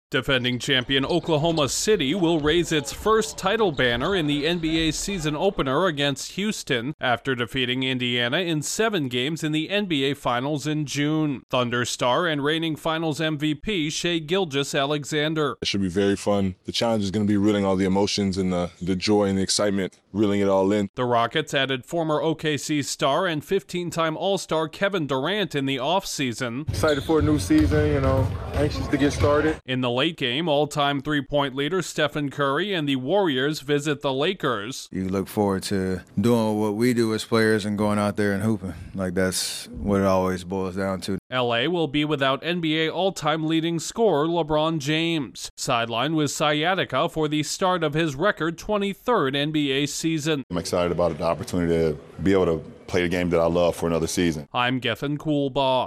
The NBA season begins Tuesday night with a doubleheader featuring hopeful title contenders. Correspondent